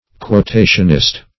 Meaning of quotationist. quotationist synonyms, pronunciation, spelling and more from Free Dictionary.
quotationist.mp3